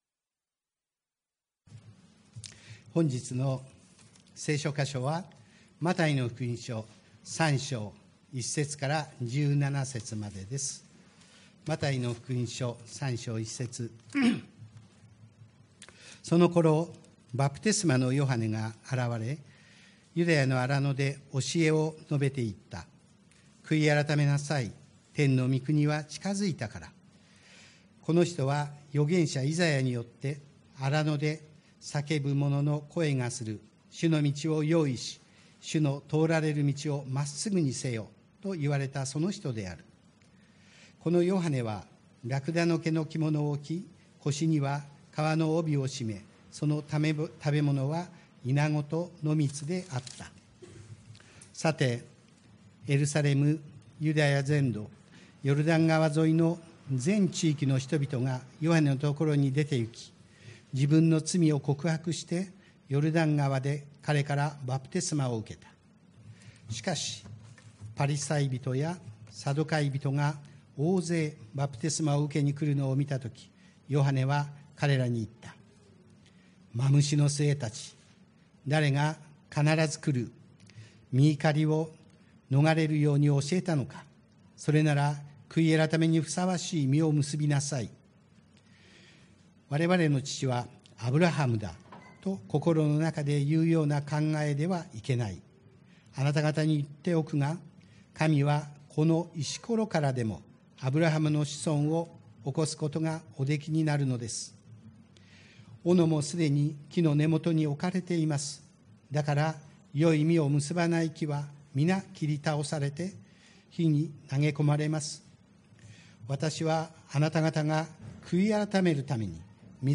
TOP > 礼拝メッセージ(説教) > 内と外を一つにする 内と外を一つにする 2021 年 3 月 28 日 礼拝メッセージ(説教